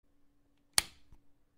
جلوه های صوتی
دانلود صدای دکمه از ساعد نیوز با لینک مستقیم و کیفیت بالا